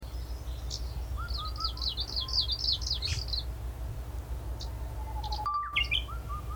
Marginally the commoner of the “Arctic warblers” on Baekryeong, with 6+ heard calling and singing (most on the 5th), with only 3-4 Arctic Warblers P. borealis noted during the same period.
Kamchatka-Leaf-Warbler.mp3